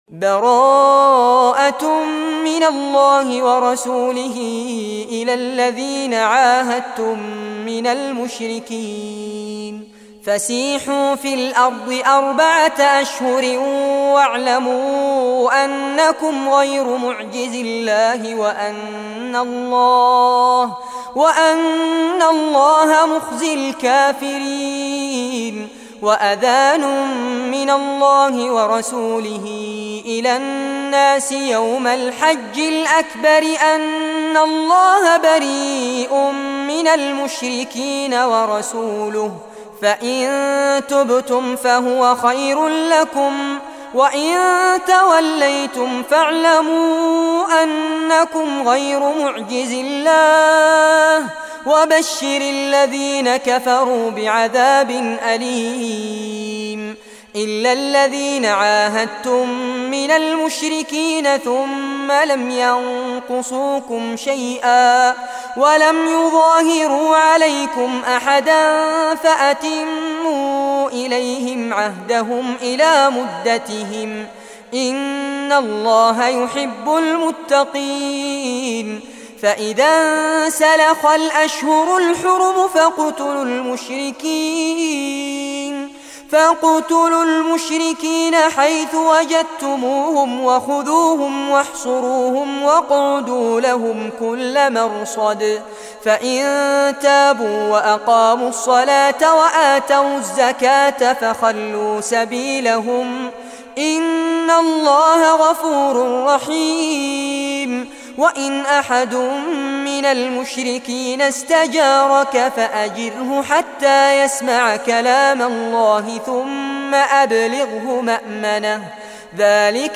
Surah At-Tawbah Recitation